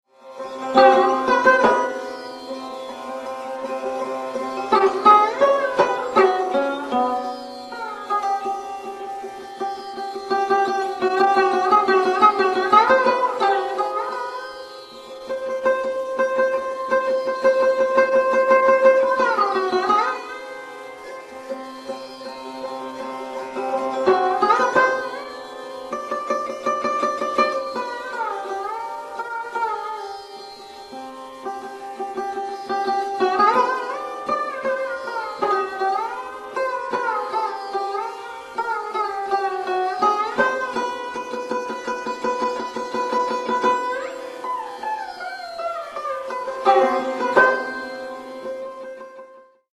An intricate ten-toned raga
• Tanpura: Sa–Pa